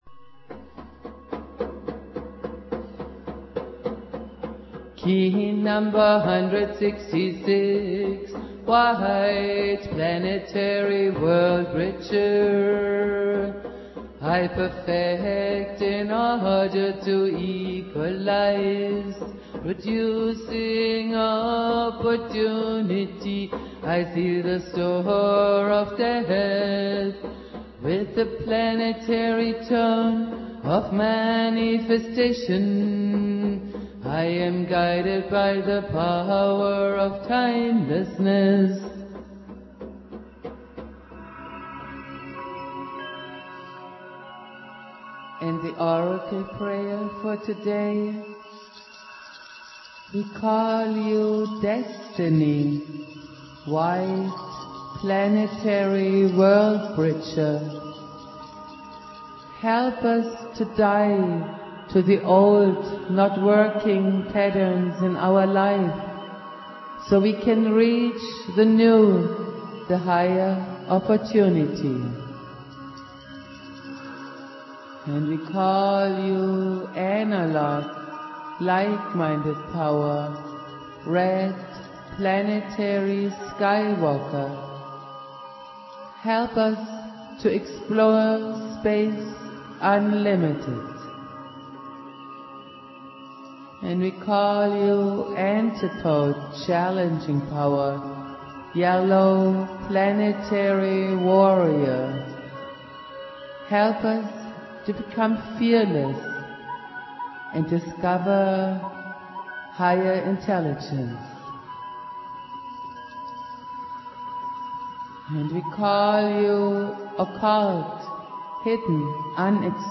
Prayer
Jose Argüelles playing flute
produced at High Flowing Recording Studio